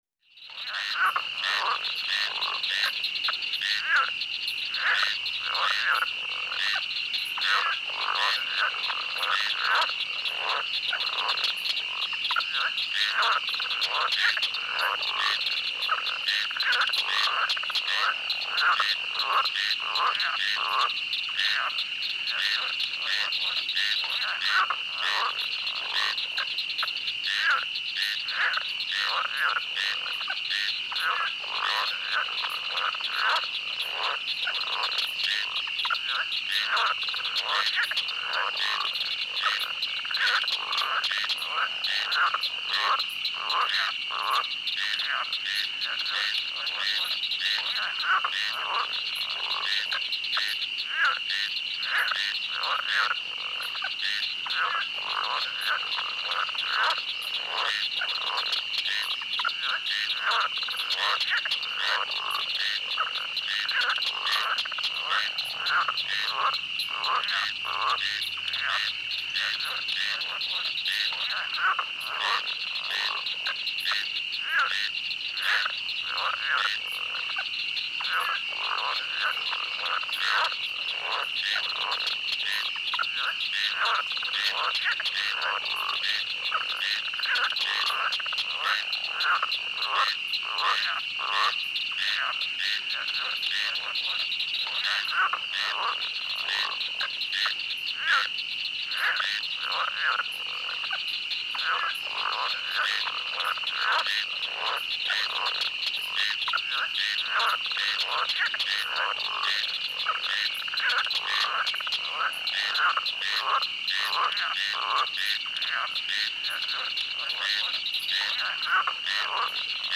Звуки болота
Шепот ночного болота с лягушачьим кряканьем